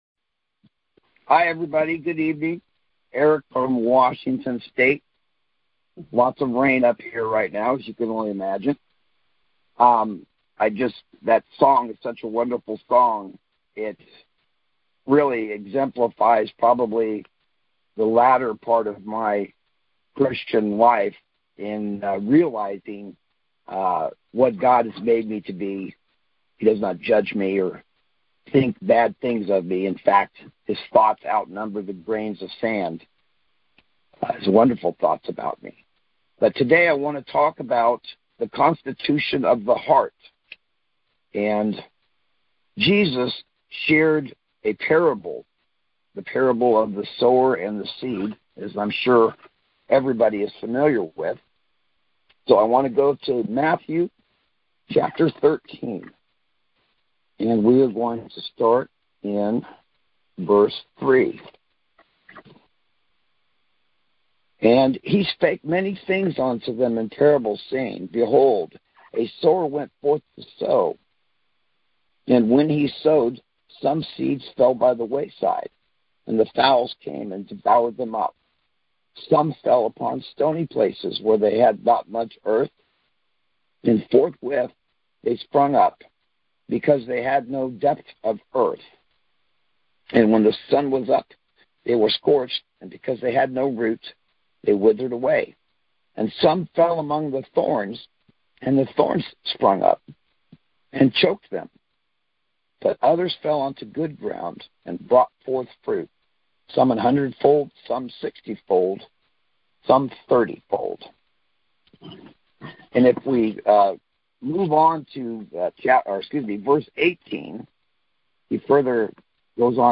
Constitution of the Heart Details Series: Conference Call Fellowship Date: Wednesday, 16 December 2020 Hits: 536 Play the sermon Download Audio ( 2.81 MB )